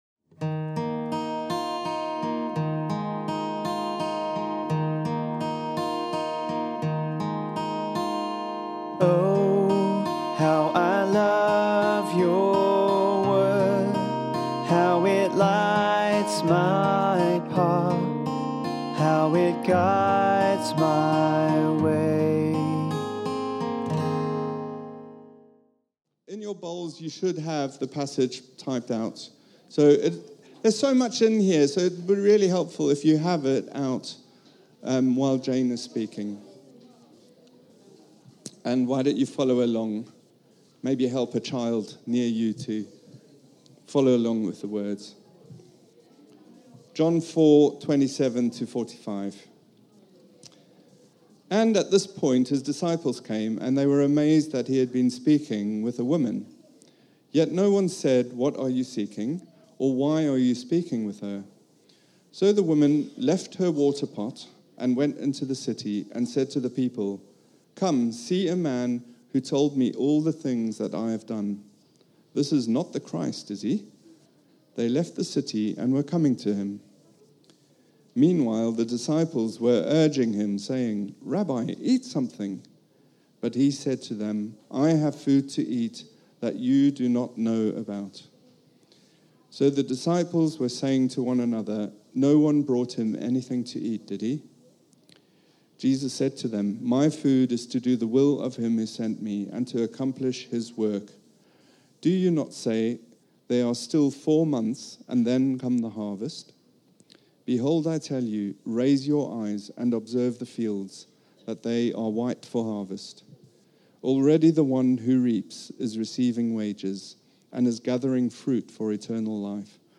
At our 3t all-age gathering